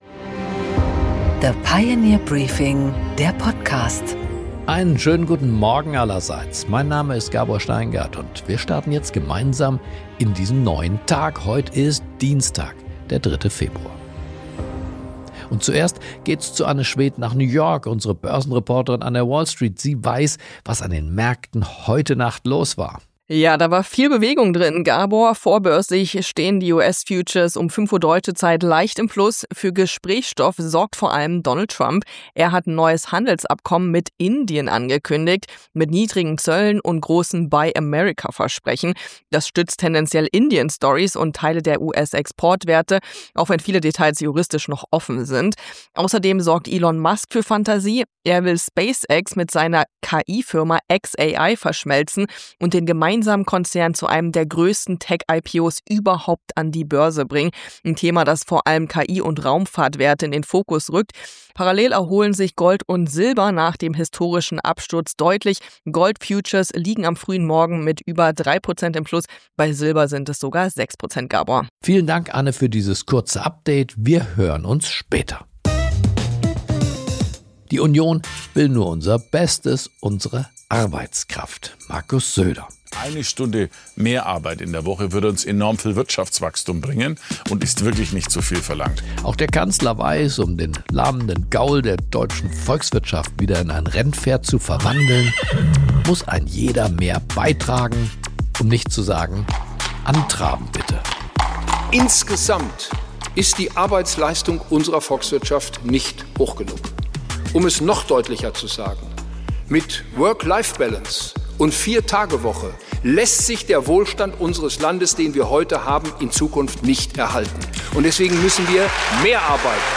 Gabor Steingart präsentiert das Pioneer Briefing.
Im Gespräch: Florence Gaub, NATO‑Strategin, spricht mit Gabor Steingart über Donald Trump, die Zukunft des Bündnisses und warum sie trotz allem eine realistische Chance sieht, dass die Kampfhandlungen in der Ukraine noch in diesem Jahr enden.